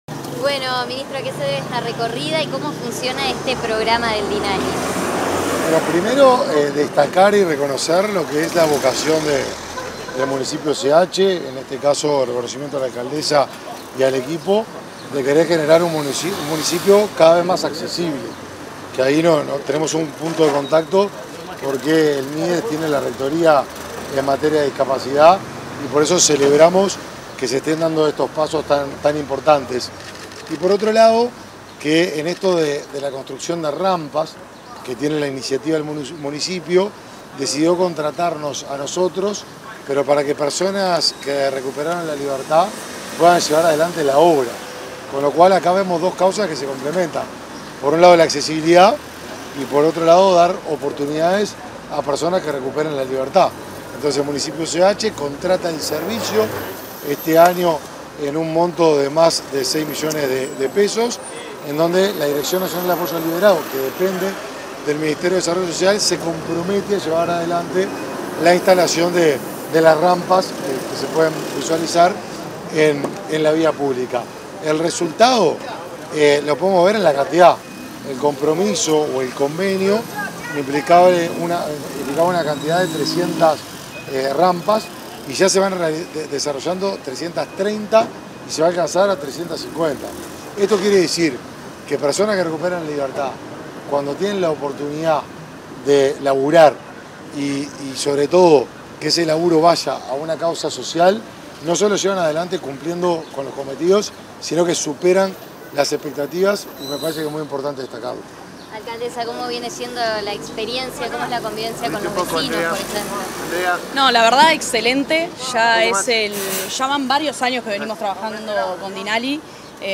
Palabras del ministro de Desarrollo Social y de la alcaldesa del municipio CH